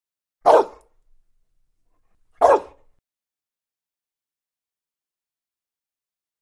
DogGoldenLabBark AT021501
Dog, Golden Lab, Bark. Two, Single Quick Barks. [close Stereo],